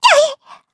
Taily-Vox_Damage_kr_04.wav